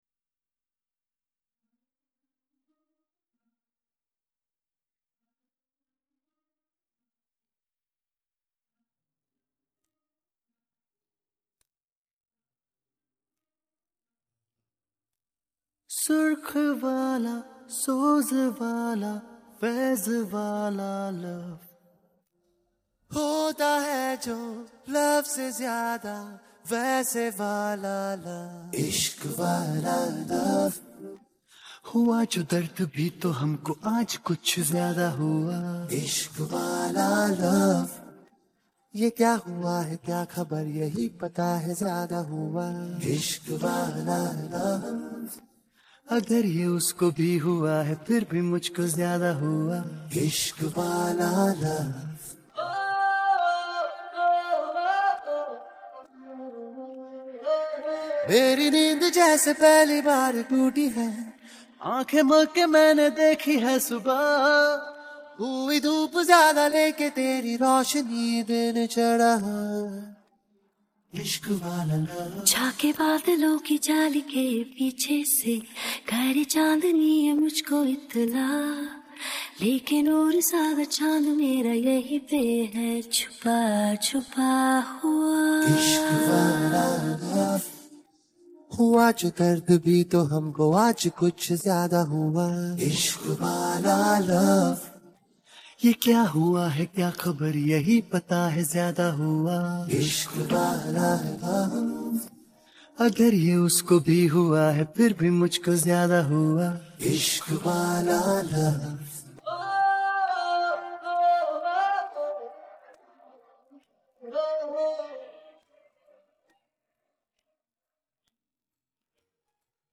Vokaldel